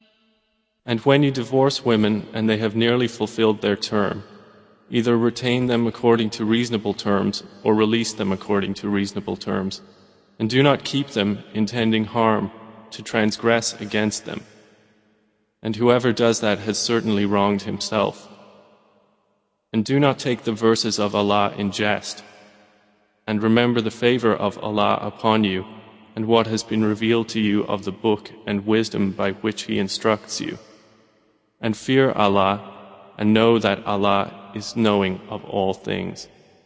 متن، ترجمه و قرائت قرآن کریم